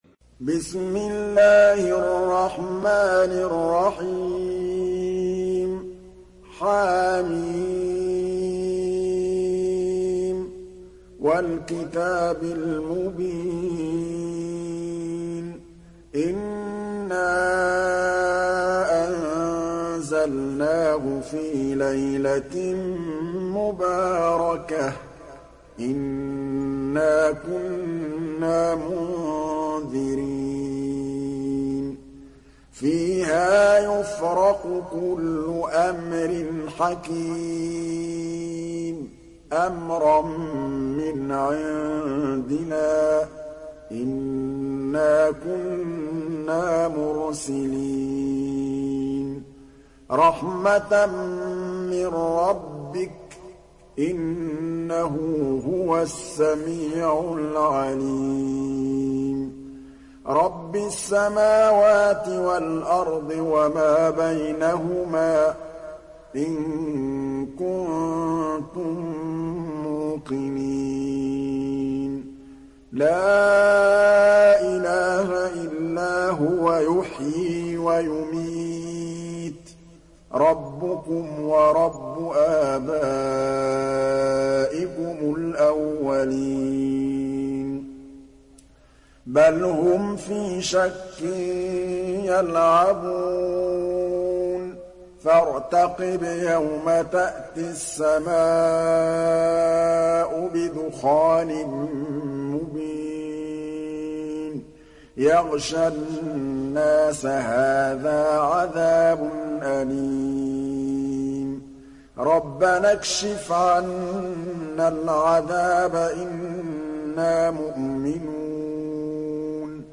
دانلود سوره الدخان mp3 محمد محمود الطبلاوي روایت حفص از عاصم, قرآن را دانلود کنید و گوش کن mp3 ، لینک مستقیم کامل